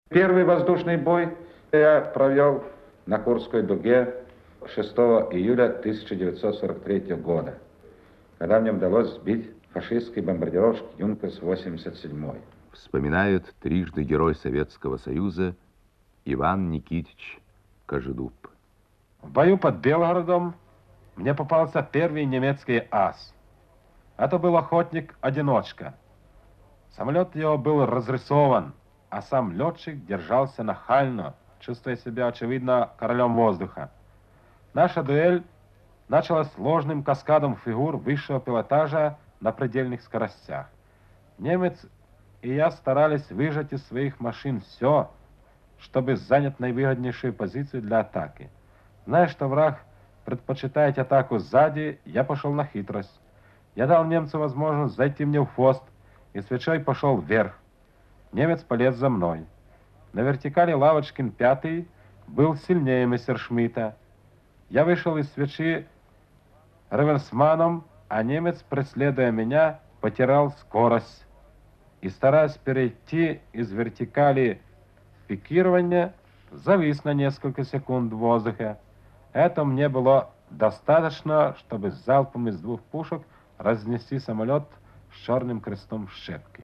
Маршал авиации, трижды Герой Советского Союза Ивана Кожедуб вспоминает о своем первом воздушном бое, проведенном в небе над Курской дугой (Архивная запись).